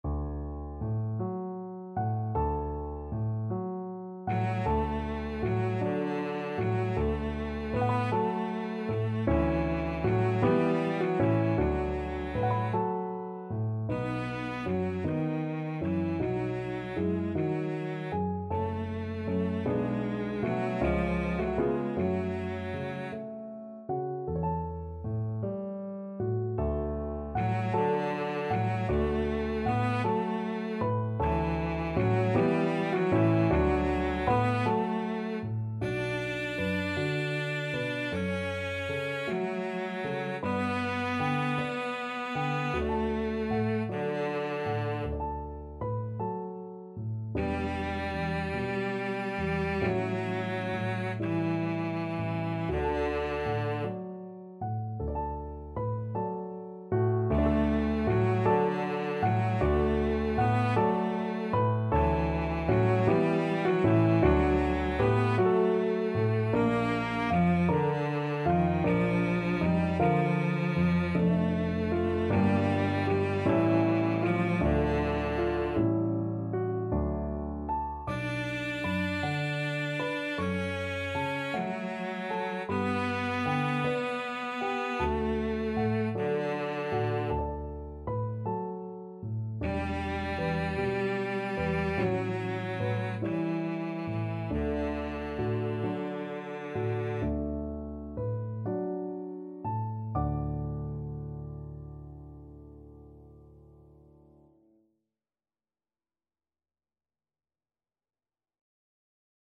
6/8 (View more 6/8 Music)
~. = 52 Allegretto
D4-D5
Classical (View more Classical Cello Music)